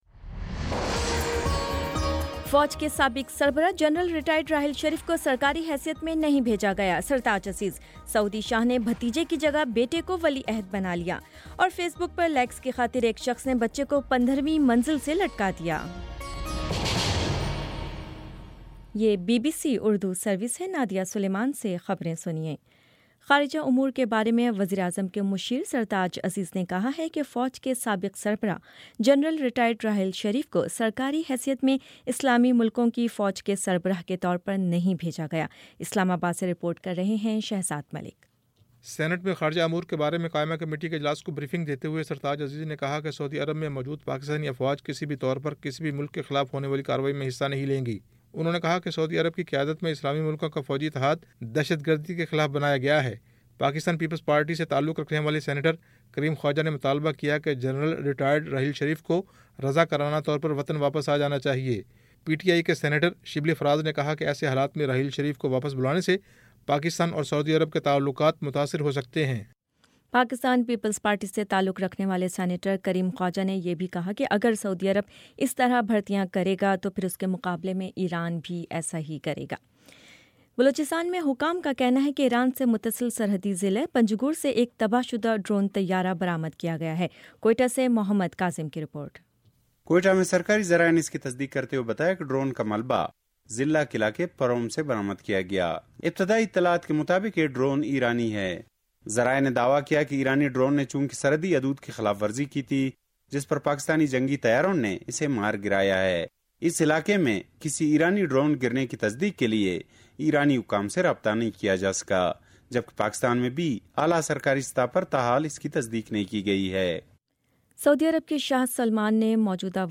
جون 21 : شام پانچ بجے کا نیوز بُلیٹن